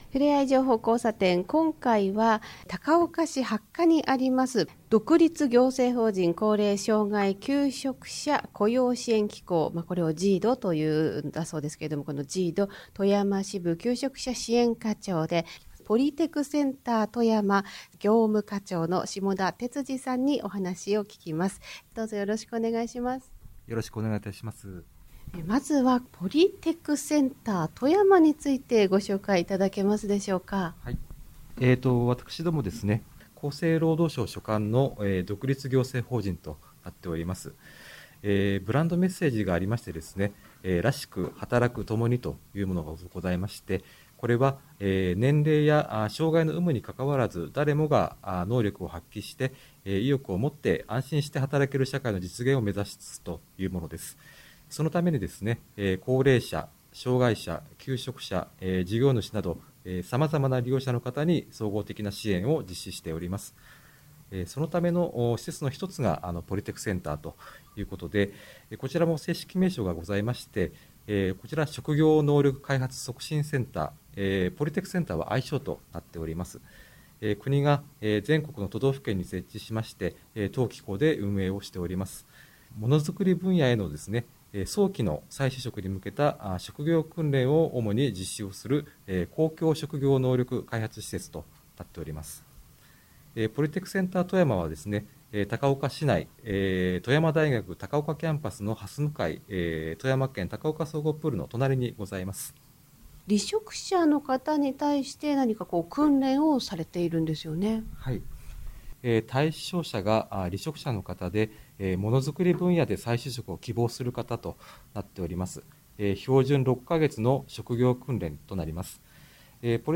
令和 7年03月18日 在職者 富山シティFM「ふれあい情報交差点」に出演いたしました。 収録音声は、 ふれあい情報交差点（ラジオ放送） (4.79 MB) からお聴きいただけます。